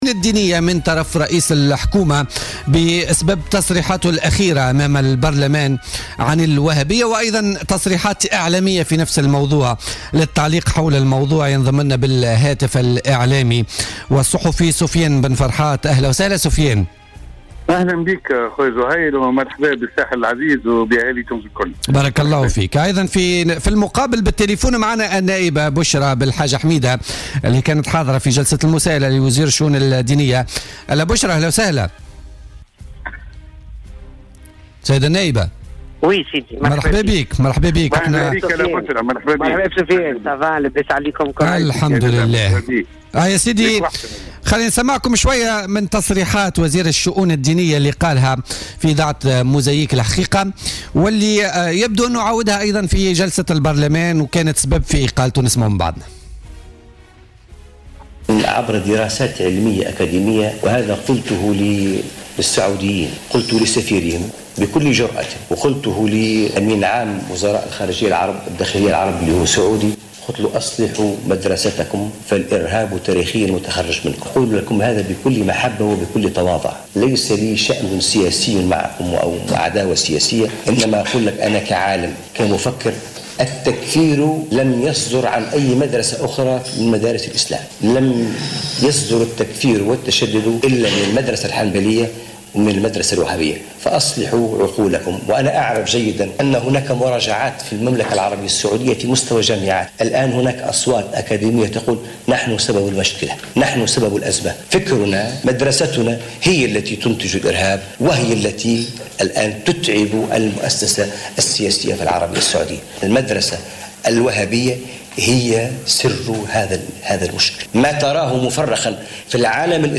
أكدت النائبة المستقلة عن حركة مشروع تونس بشرى بلحاج حميدة في مداخلة لها في بوليتيكا اليوم الجمعة 4 نوفمبر 2016 أن إقالة وزير الشؤون الدينية على خلفية تصريحاته امام البرلمان بخصوص السعودية والوهابية أمر طبيعي لأن هذا الشخص يغير مواقفه على أهوائه.